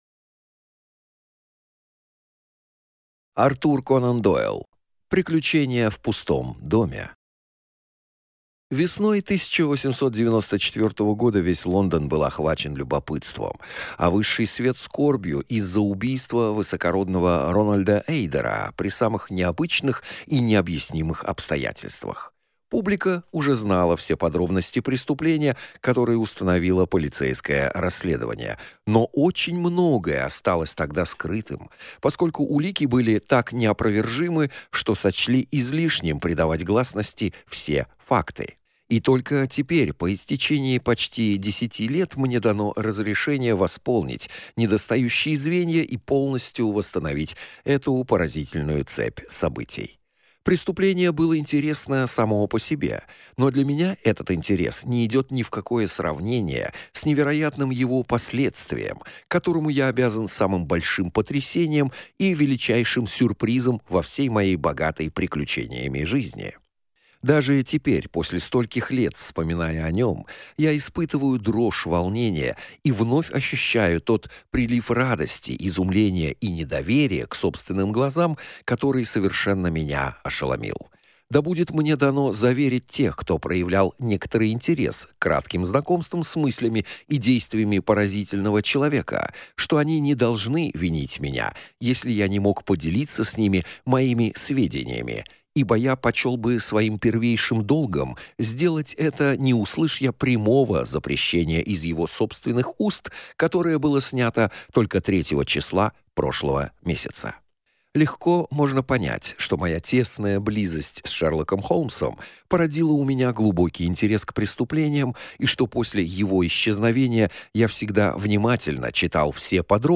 Аудиокнига Приключение в пустом доме | Библиотека аудиокниг
Прослушать и бесплатно скачать фрагмент аудиокниги